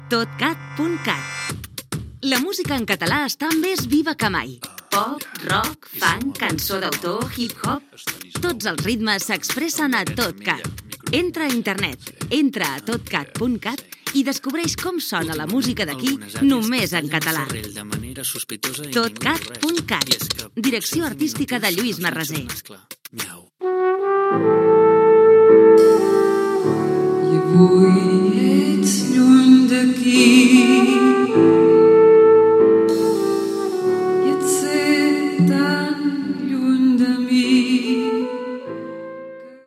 Indicatiu del canal i tema musical